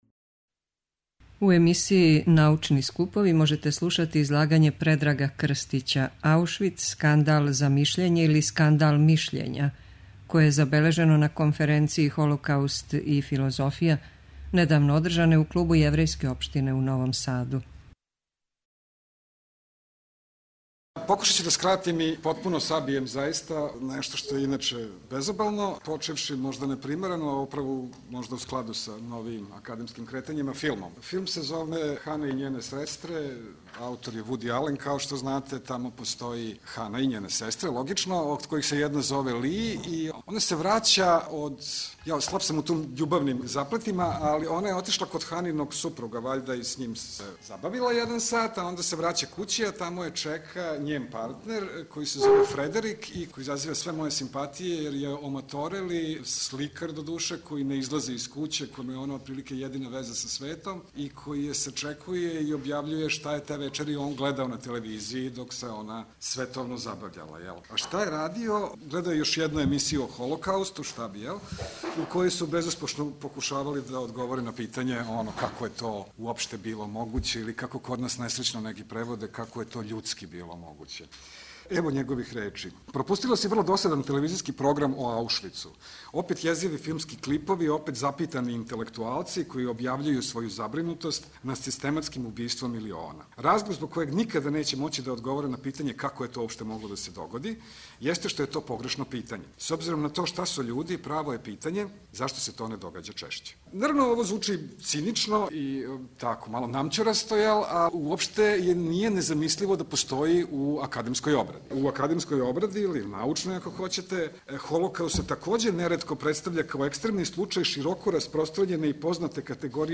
преузми : 10.81 MB Трибине и Научни скупови Autor: Редакција Преносимо излагања са научних конференција и трибина.